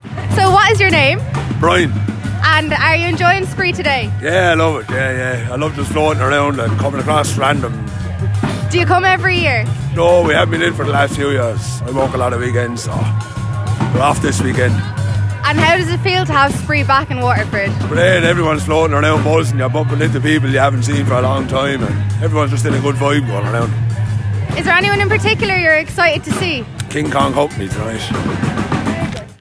This man